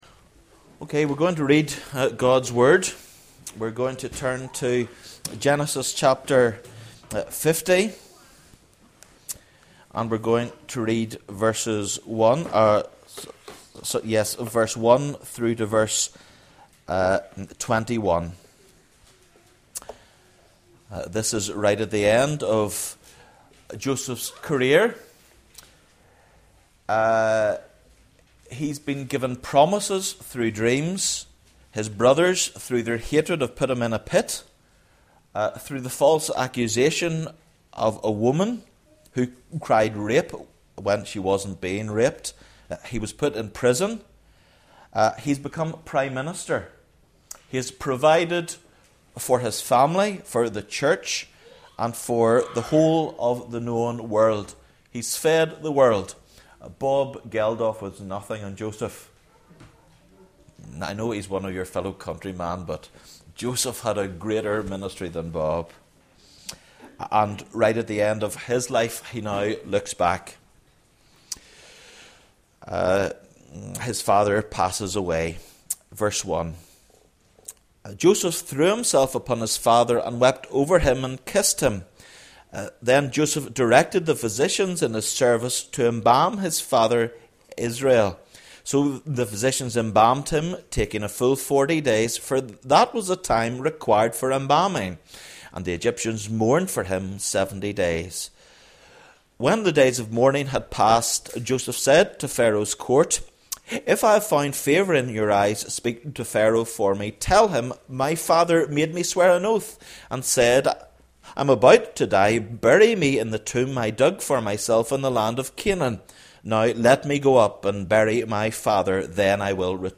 Sermons at New Life Fellowship